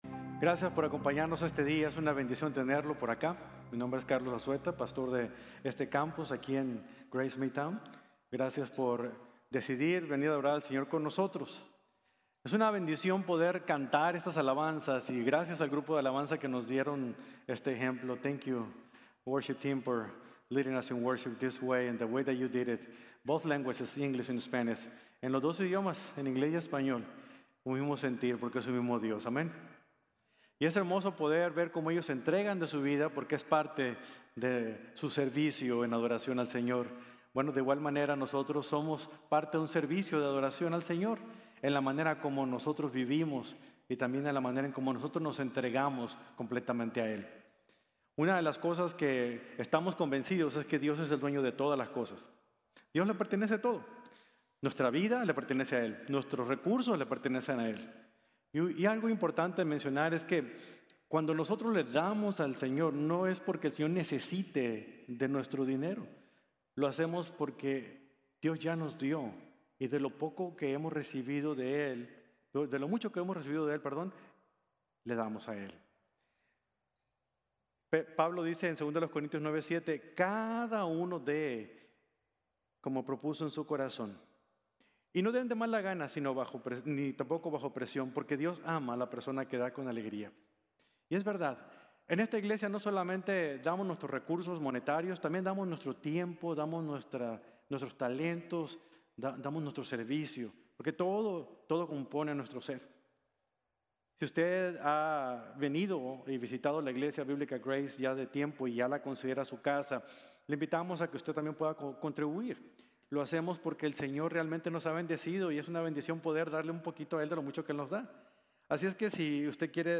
Una Mente Transformada | Sermon | Grace Bible Church